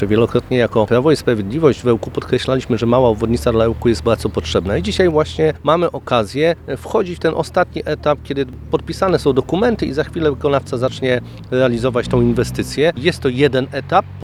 Poseł Kossakowski zapewnił o tym, że o dobre rozwiązania dla Ełku i regionu zabiega w Warszawie, a pomocne są w tym środki z Funduszu Dróg Samorządowych.